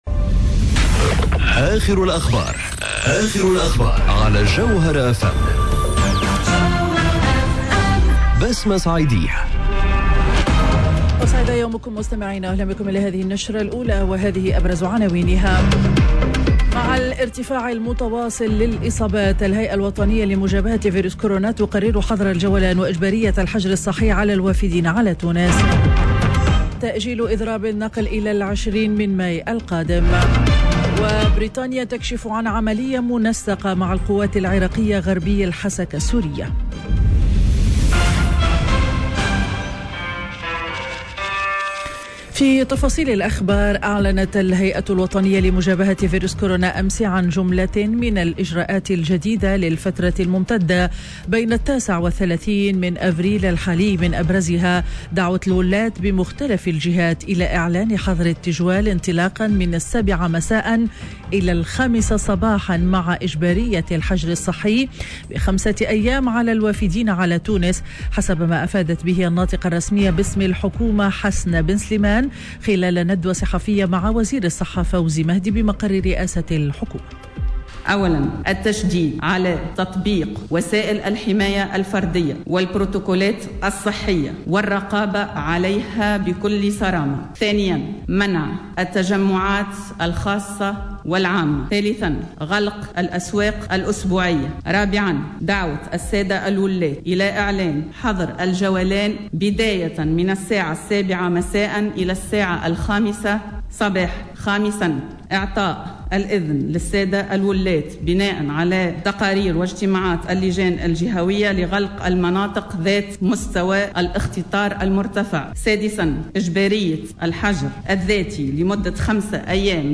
نشرة أخبار السابعة صباحا ليوم الخميس 08 أفريل 2021